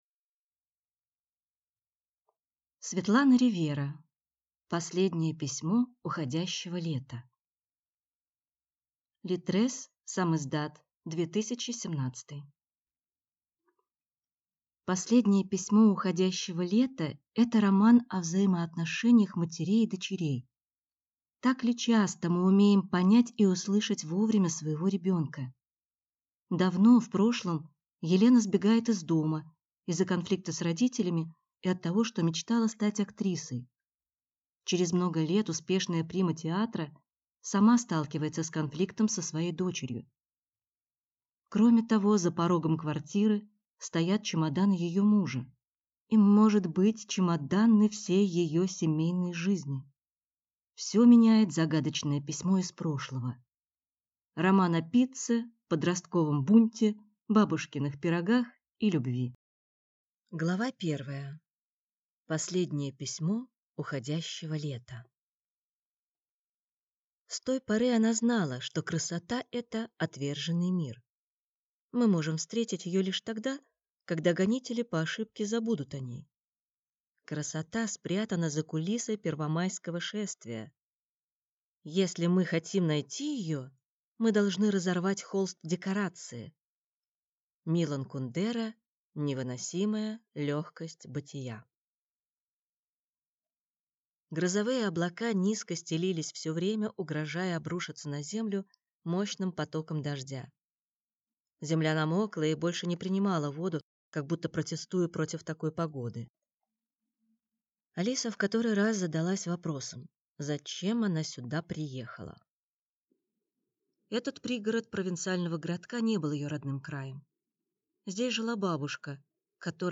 Аудиокнига Последнее письмо уходящего лета | Библиотека аудиокниг